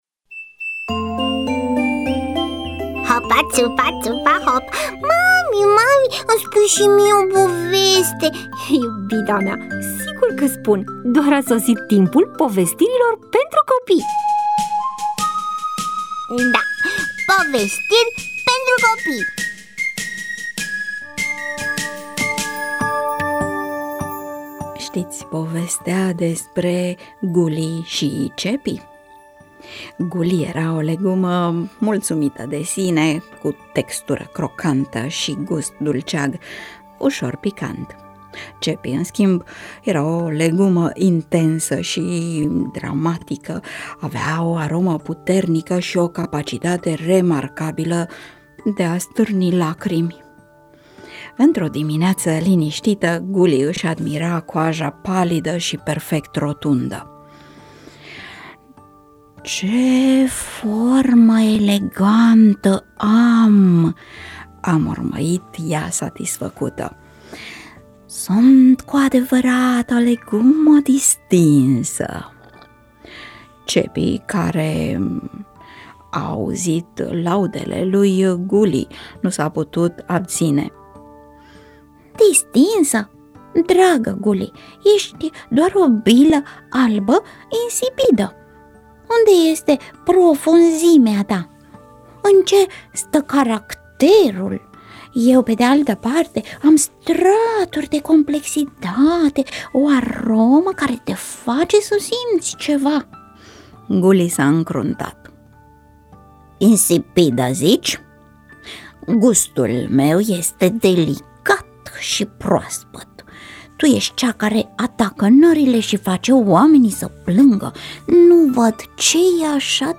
EMISIUNEA: Povestiri pentru copii